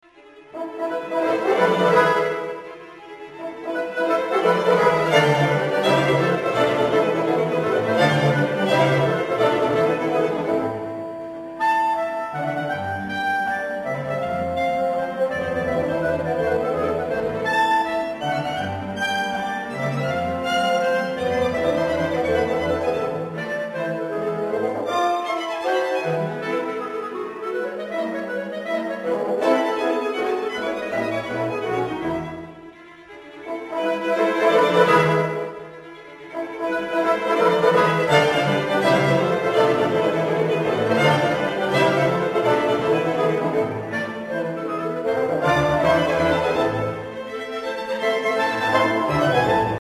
Tarantella (allegro vivace)
Natuurlijk was Blanc wel zo slim om wat water bij de wijn te doen: niet zelden balanceert zijn muziek op de rand van de onvervalste salondeun.
Opvallend zijn verder de virtuoze passages voor klarinet en viool in het eerste deel, en de dramatische inleiding van de finale. Hoogtepunt van het septet is zonder twijfel het derde deel, de Tarantella, waarin alle ritmische vindingrijkheid en melodisch vernuft in elkaar passen.